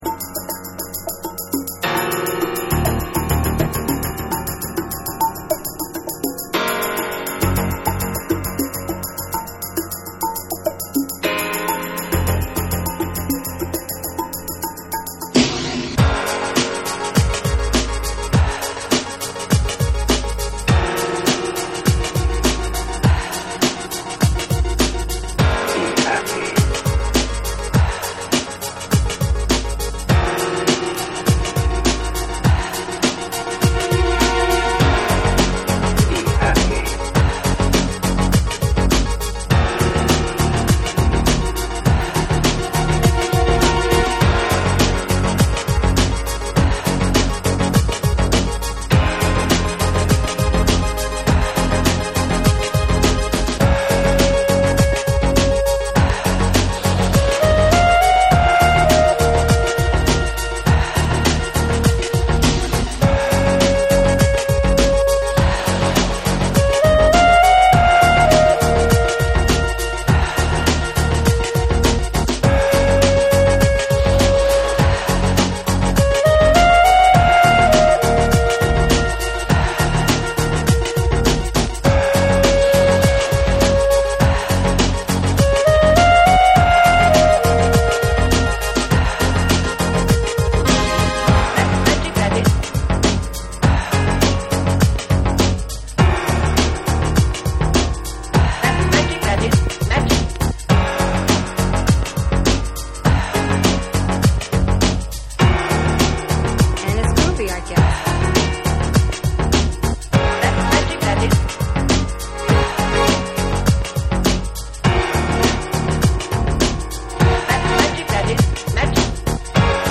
BREAKBEATS / CHILL OUT